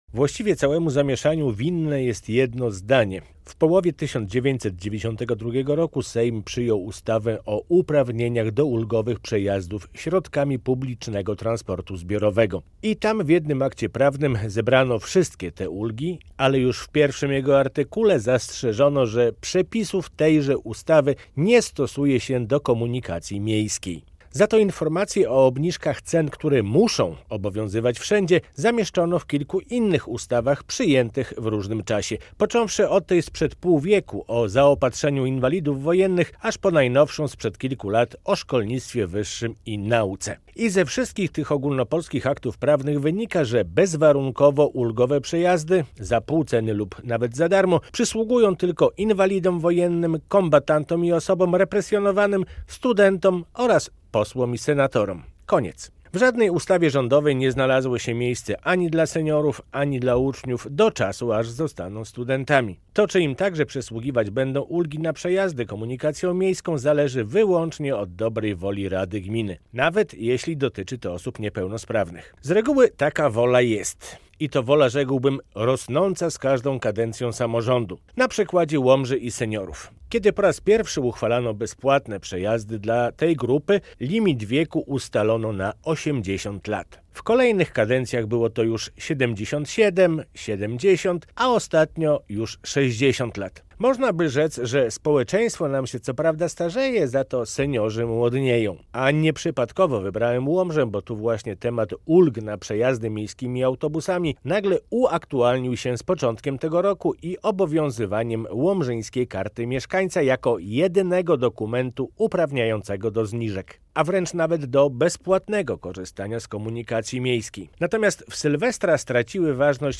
Ulgi w komunikacji miejskiej - felieton